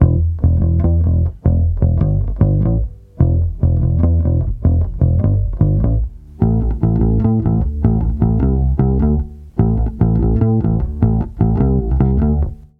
罗德钢琴从爵士乐的心情循环播放
Tag: 75 bpm Jazz Loops Piano Loops 2.15 MB wav Key : D